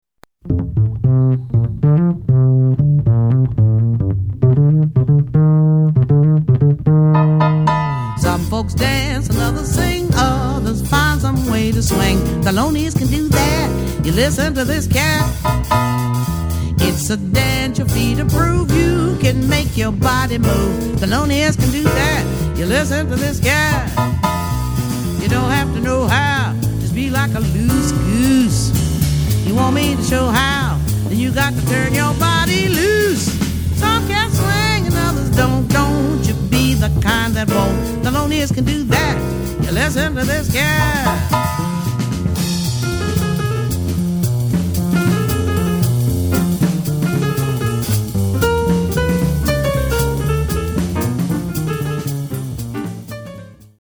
tenor saxophonist